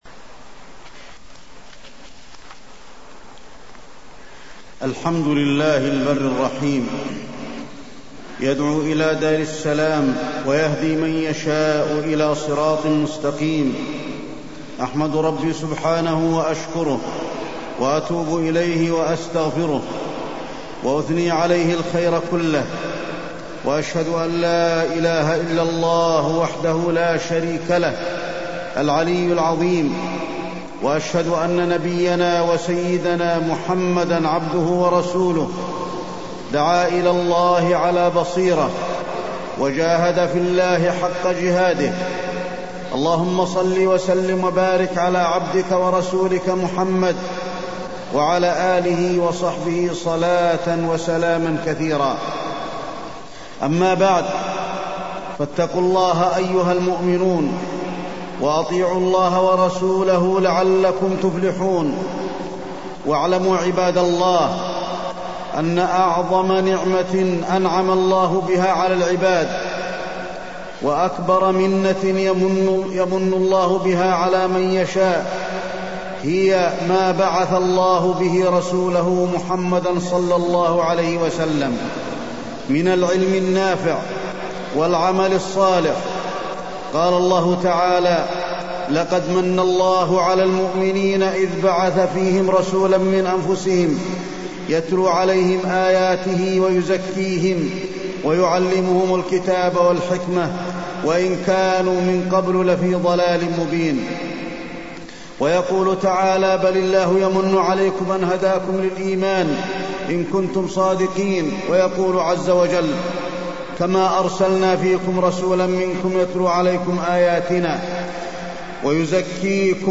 تاريخ النشر ١٤ جمادى الآخرة ١٤٢٣ هـ المكان: المسجد النبوي الشيخ: فضيلة الشيخ د. علي بن عبدالرحمن الحذيفي فضيلة الشيخ د. علي بن عبدالرحمن الحذيفي الدعوة